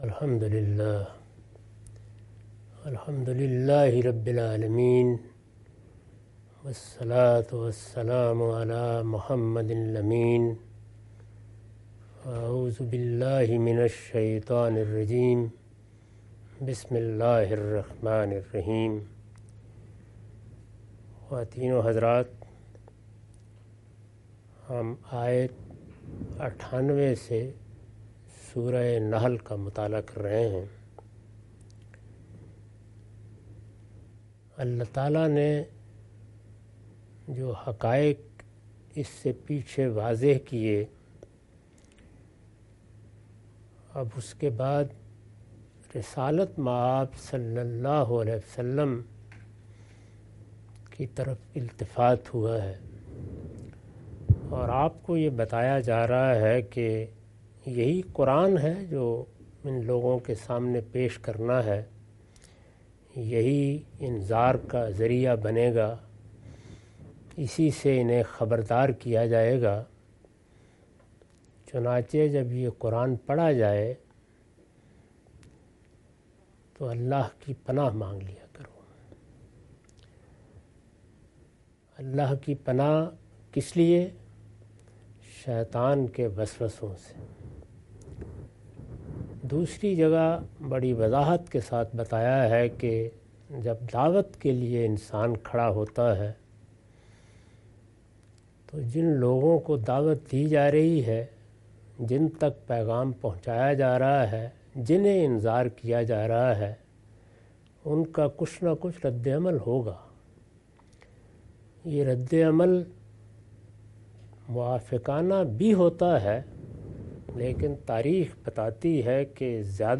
Surah Al-Nahl- A lecture of Tafseer-ul-Quran – Al-Bayan by Javed Ahmad Ghamidi. Commentary and explanation of verses 98-102.